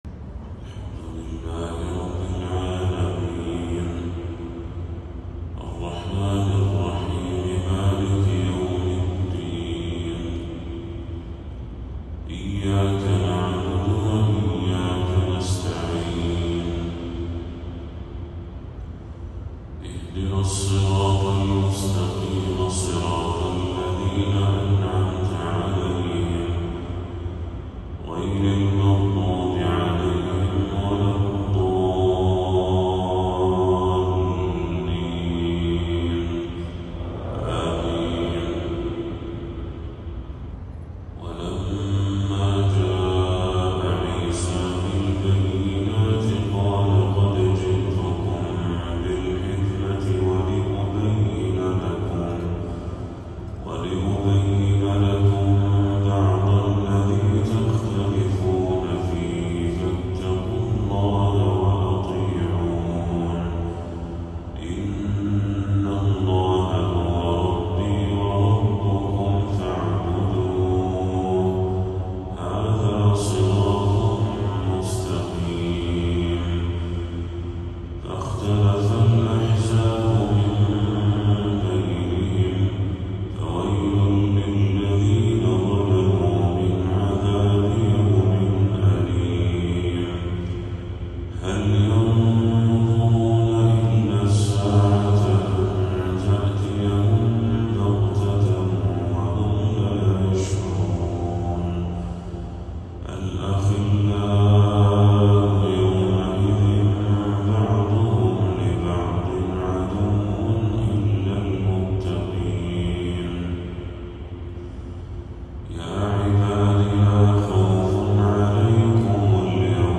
تلاوة ندية لخواتيم سورة الزخرف للشيخ بدر التركي | فجر 3 ربيع الأول 1446هـ > 1446هـ > تلاوات الشيخ بدر التركي > المزيد - تلاوات الحرمين